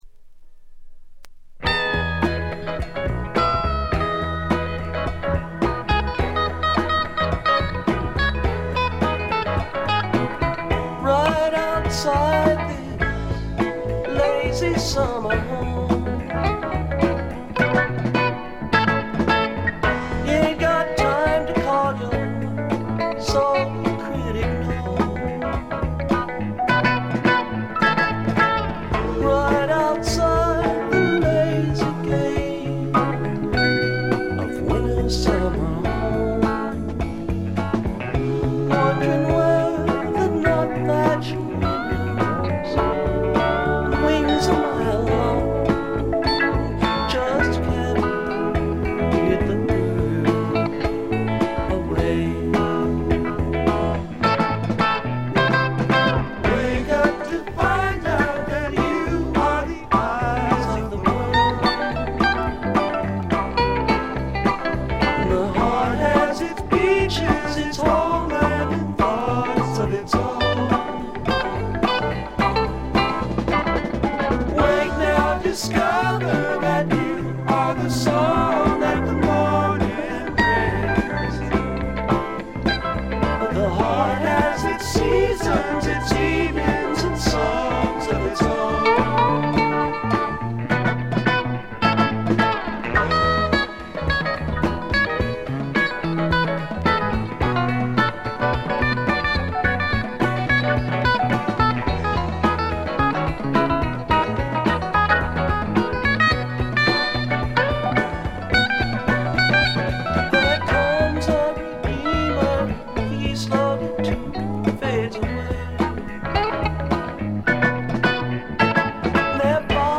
静音部で軽微なバックグラウンドノイズが聴かれる程度。
試聴曲は現品からの取り込み音源です。